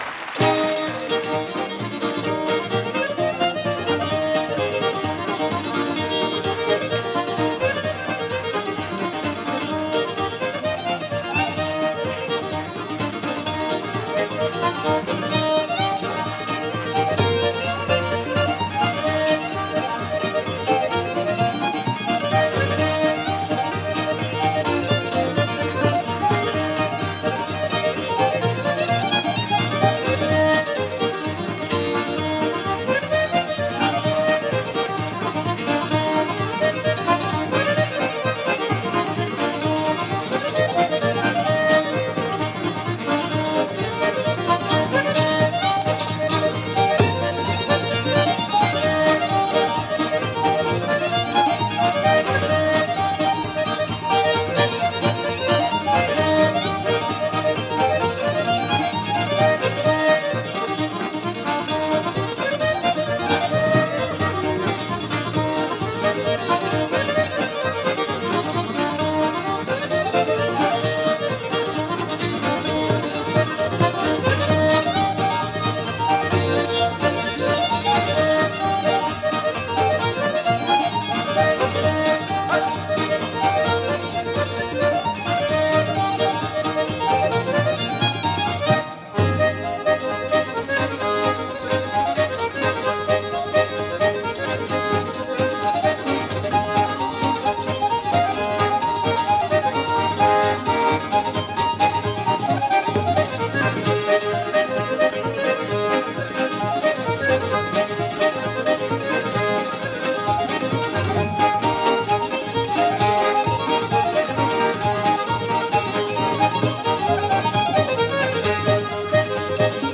风笛、小提琴等乐器逐渐发展出爱尔兰音乐的固有特色。
幸好，这是一张现场录音的演奏会唱片。